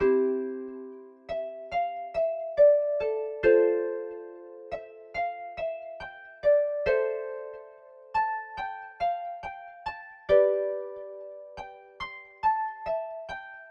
弦乐深渊竖琴140
描述：果味循环
标签： 140 bpm Dubstep Loops Strings Loops 2.31 MB wav Key : Unknown
声道立体声